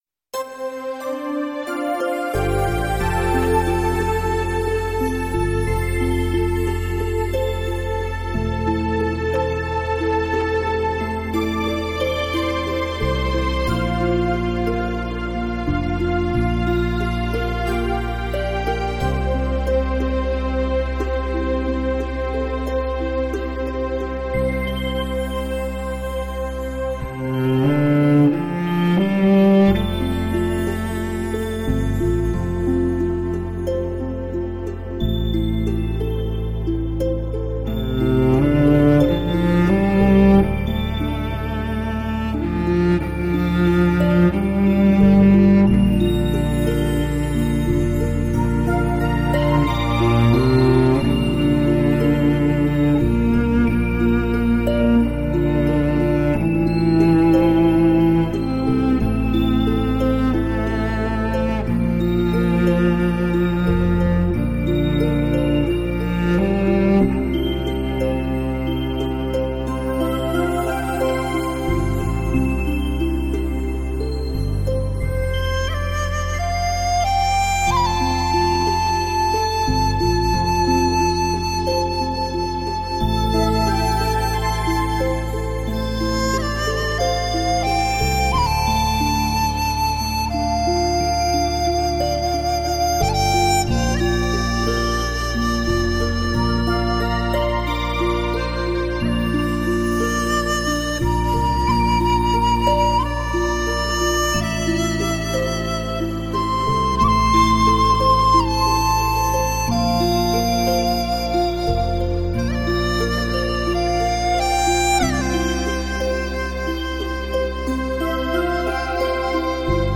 Surround 7.1德国技术STS三维高临场音效、美国最新技术高清录音紫水晶CD。